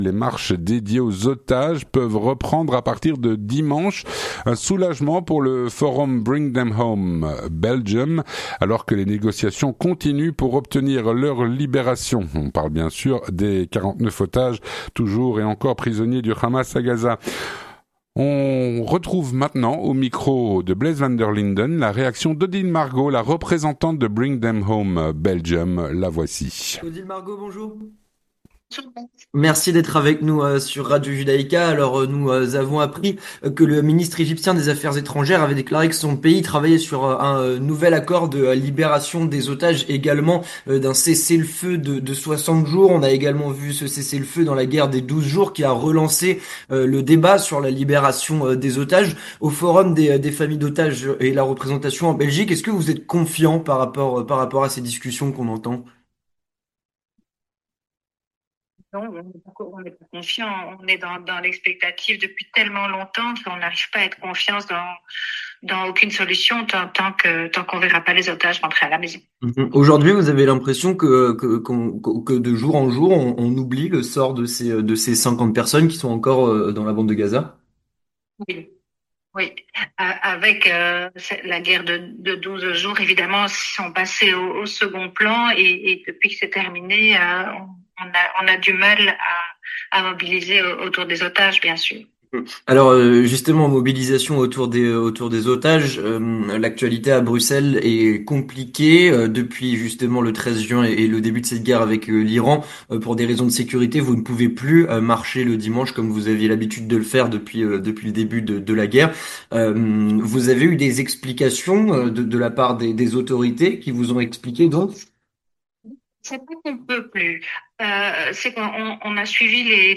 L'entretien du 18H - Les marches dédiées aux otages peuvent reprendre à partir de ce dimanche.